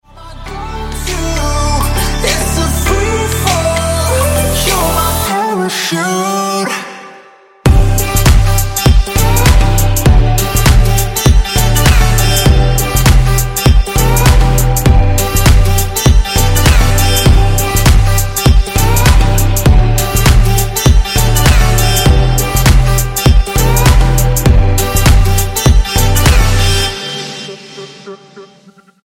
2016 Pop Single
Style: Pop